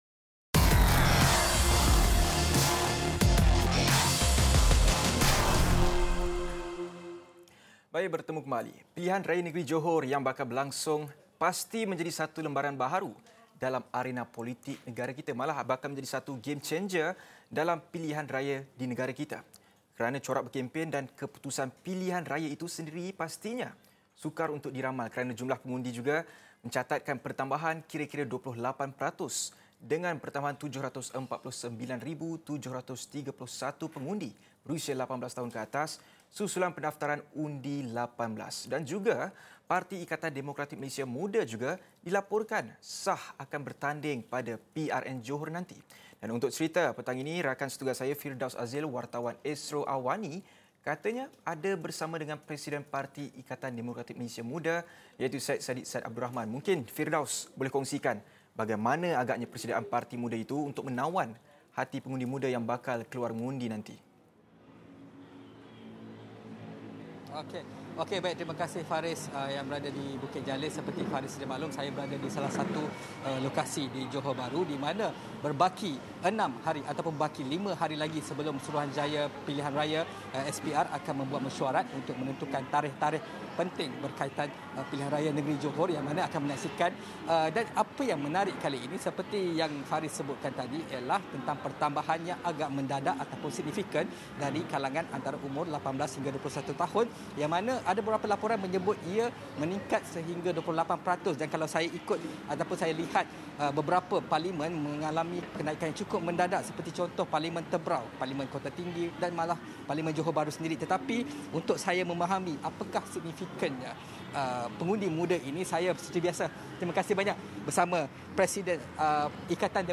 sempat menemu bual Presiden Parti Ikatan Demokratik Malaysia (MUDA), Syed Saddiq Syed Abdul Rahman bagi mengulas lebih lanjut mengenai pengundi muda yang dikatakan akan menjadi 'game changer' dalam Pilihan Raya Negeri (PRN) Johor nanti.